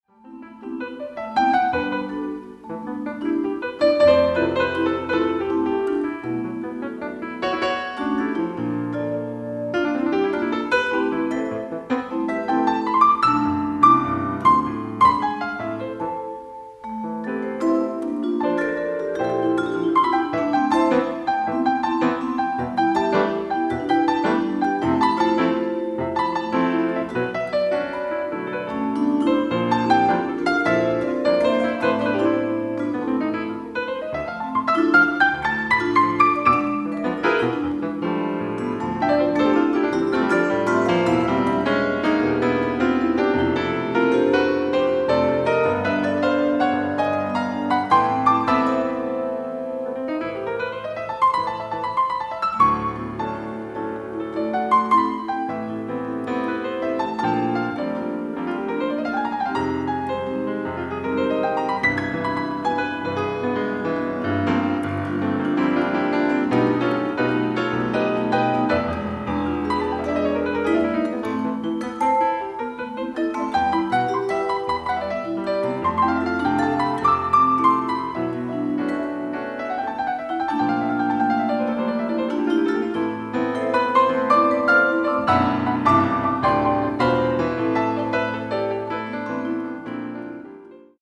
ライブ・アット・ミハイロフスキー劇場、サンクト・ペテルブルグ、ロシア 03/28/2008
※試聴用に実際より音質を落としています。